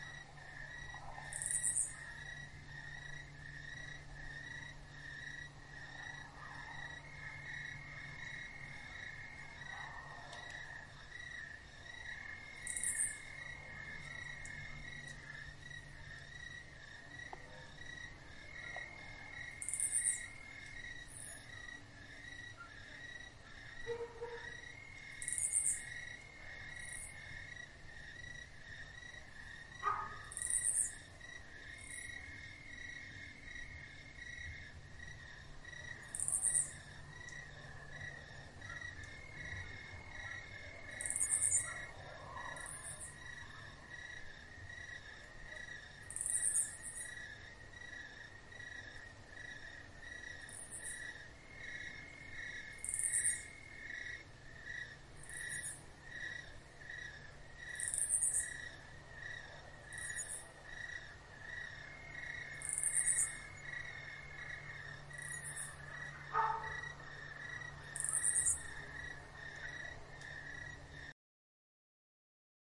随机 " 蟋蟀 乡村田野的夜晚 漂亮的鸣叫声 + 遥远的道路
描述：蟋蟀国家领域晚上好唧唧喳喳+遥远的road.flac
Tag: 鸣叫 蟋蟀 晚上 国家